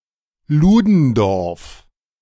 Erich Friedrich Wilhelm Ludendorff (German: [ˈeːʁɪç ˈfʁiːdʁɪç ˈvɪlhɛlm ˈluːdn̩dɔʁf]